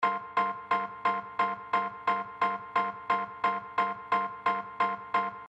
一个简单的罗德斯图案001
描述：一个简单的钥匙图案，可用于不同的流派。
Tag: 88 bpm Hip Hop Loops Organ Loops 939.72 KB wav Key : Unknown